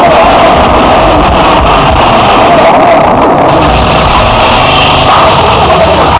gunship_crash.ogg